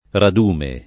[ rad 2 me ]